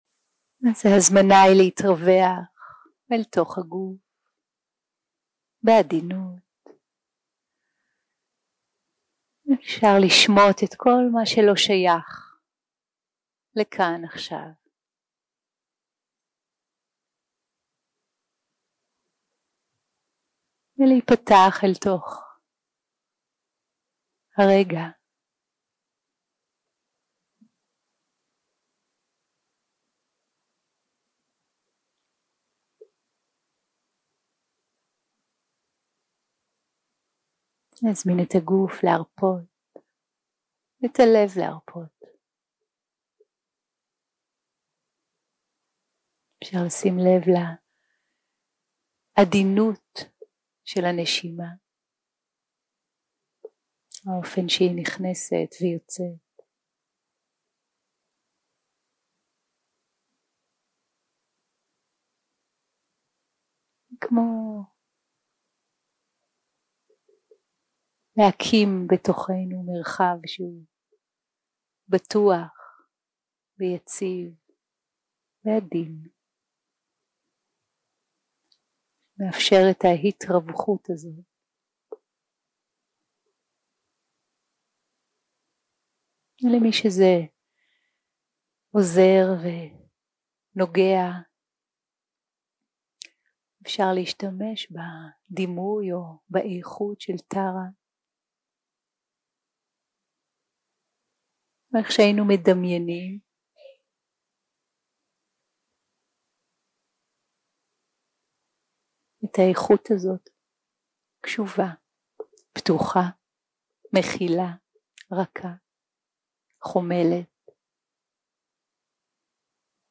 day 3 - recording 11 - Late Evevning - Meta Meditation + Chanting
day 3 - recording 11 - Late Evevning - Meta Meditation + Chanting 0:00 0:00 סוג ההקלטה: Dharma type: Guided meditation שפת ההקלטה: Dharma talk language: Hebrew